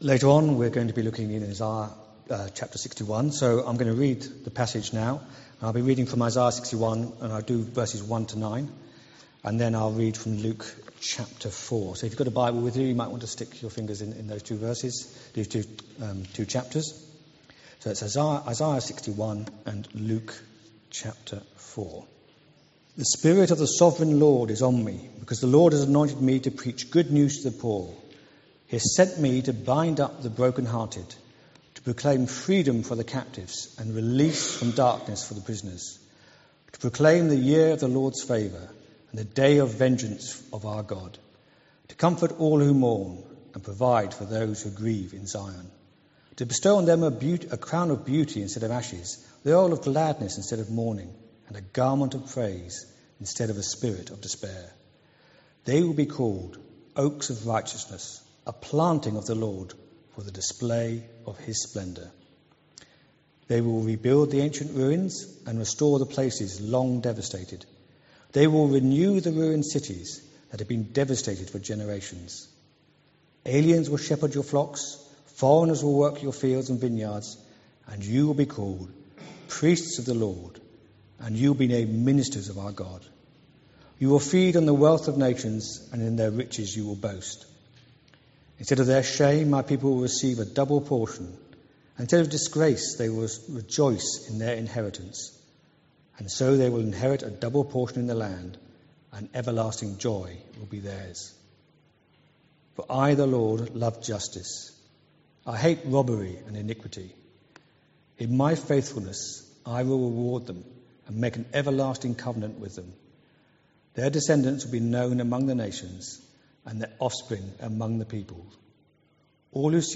An audio file of the service is available to listen to here.